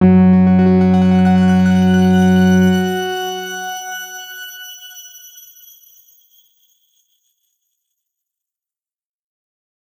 X_Grain-F#2-ff.wav